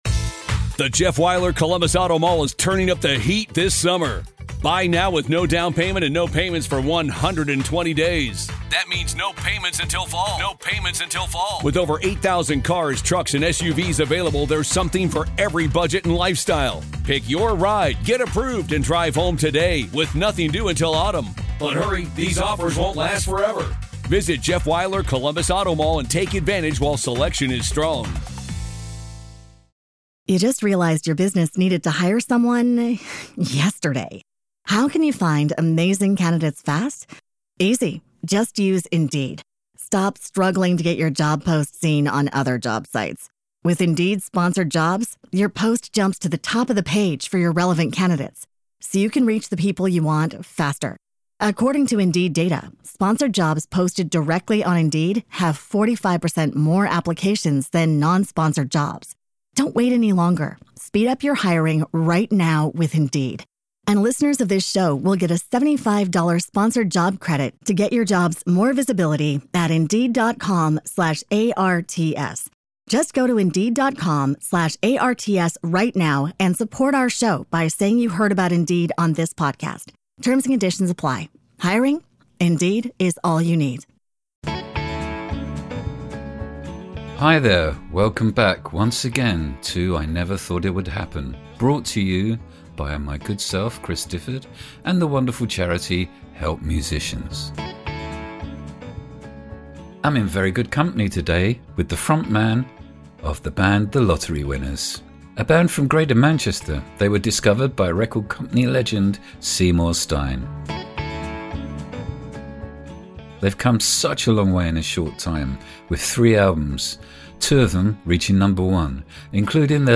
Topics in this engaging conversation include the influence of stadium-smashing frontmen like Freddie Mercury and Robbie Williams, the importance of Manchester Apollo and how teachers can change lives. Plus there's a great story about Johnny Marr.